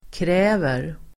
Uttal: [kr'ä:ver]